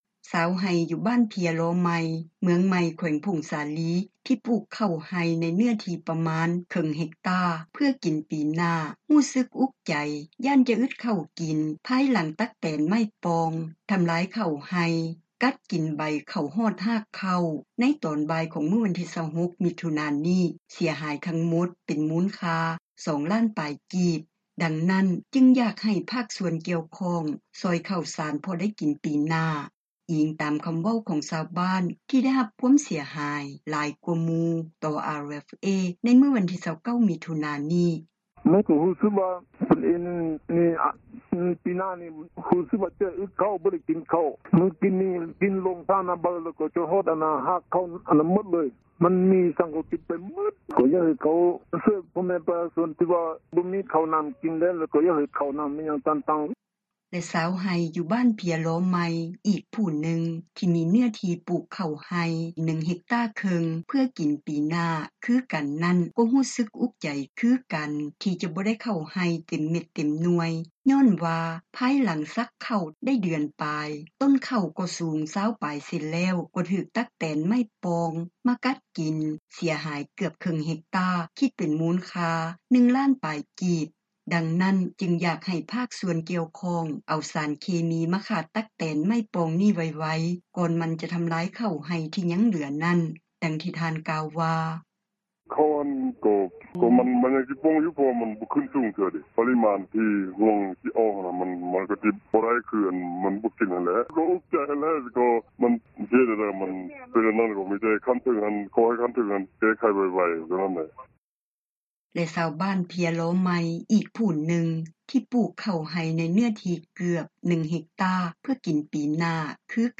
ຕັກແຕນທໍາລາຍເຂົ້າໄຮ່ ມ.ໃໝ່ ຂ.ຜົ້ງສາລີ — ຂ່າວລາວ ວິທຍຸເອເຊັຽເສຣີ ພາສາລາວ